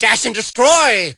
mortis_atk_vo_01.ogg